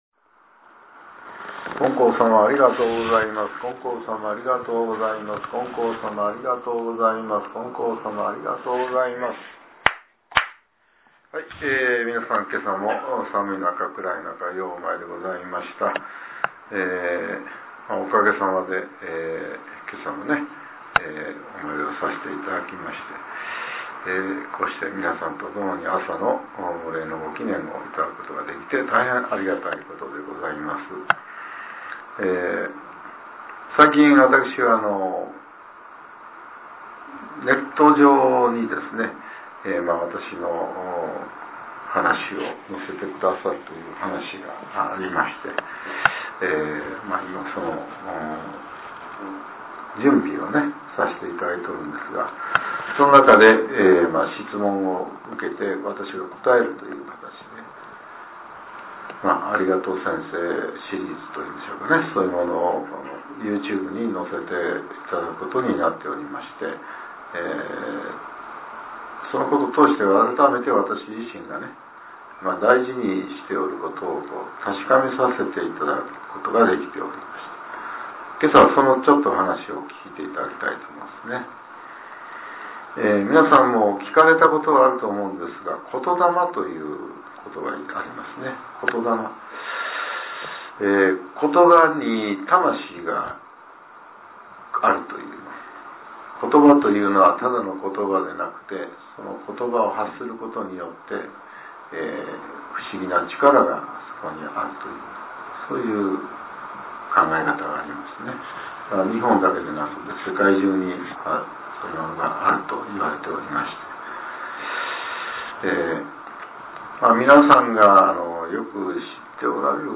令和７年３月６日（朝）のお話が、音声ブログとして更新されています。